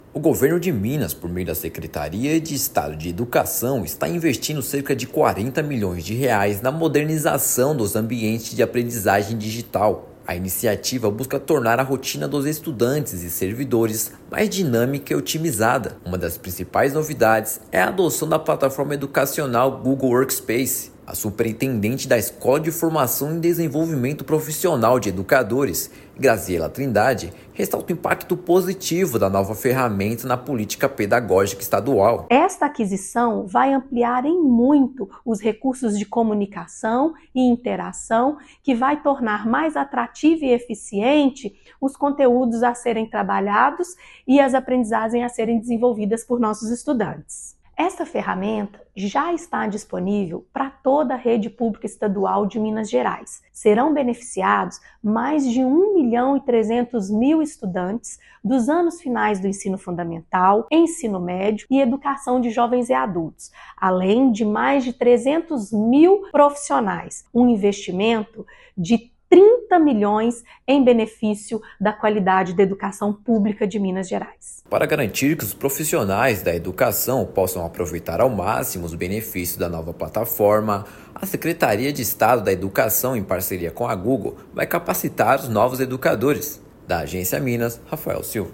[RÁDIO] Governo de Minas investe R$ 40 milhões em ferramentas digitais inovadoras na Educação